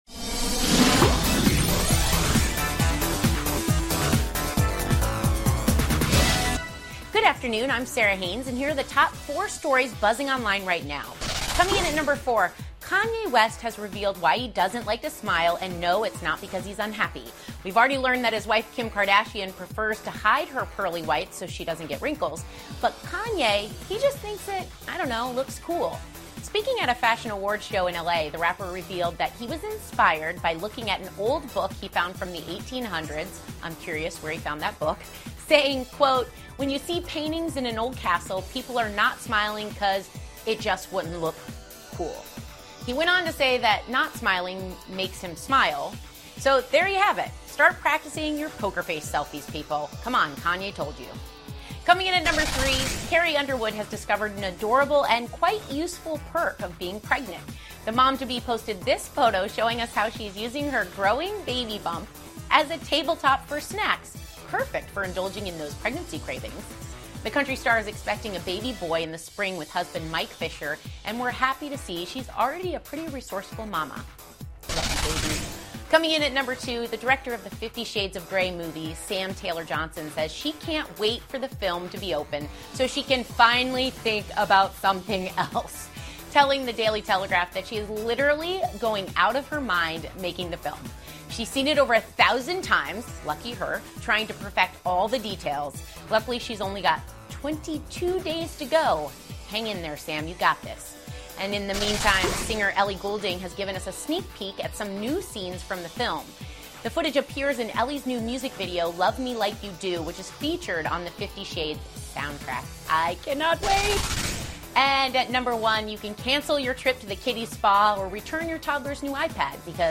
访谈录 金·卡戴珊丈夫坎耶·维斯特为什么不爱笑 听力文件下载—在线英语听力室